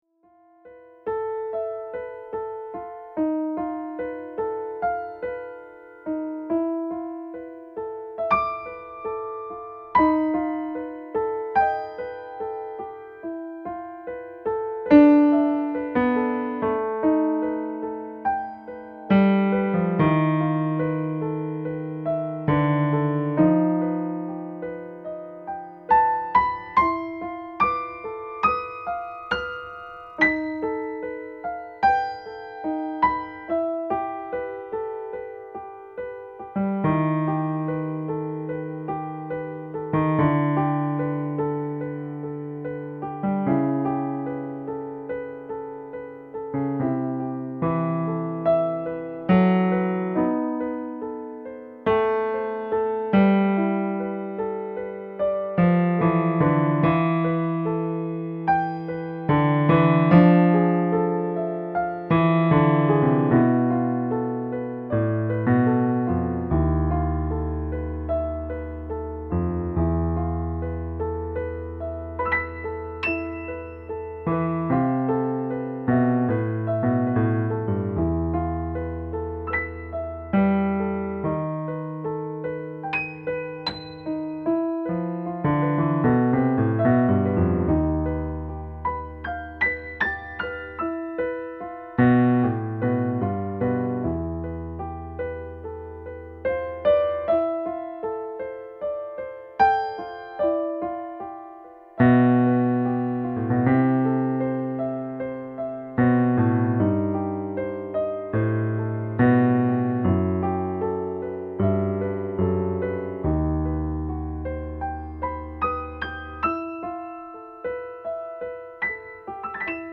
Avez-vous déjà remarqué comme le piano se prête à des atmosphères glacées ?
Comme j'ai peu de temps et que je préfère mettre quand même un petit quelque chose sur mon blog plutôt que de rester silencieux pendant un mois, je ne vais pas chercher très loin pour le titre de cette impro, et j'espère que vous ne m'en voudrez pas !